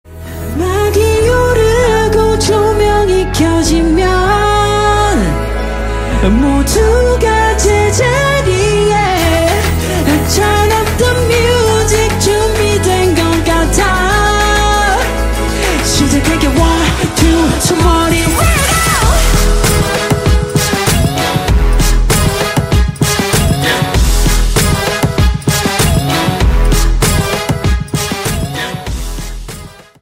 KPop